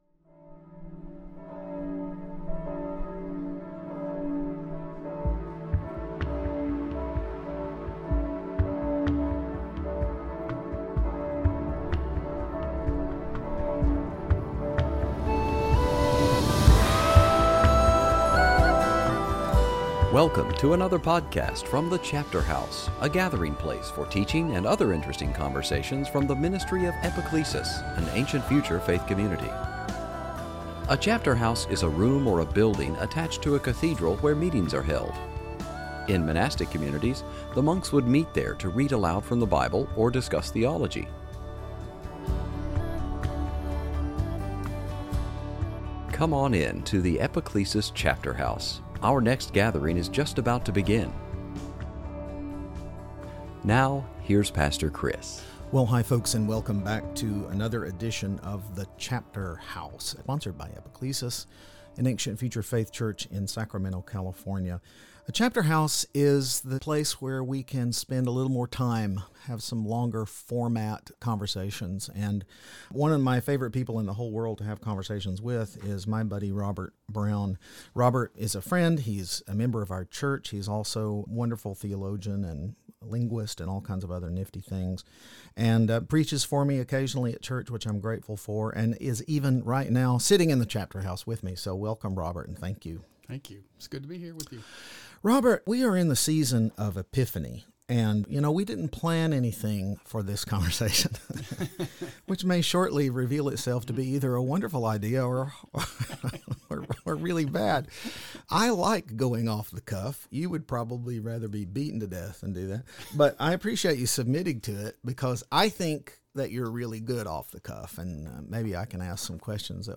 It was a delightful conversation.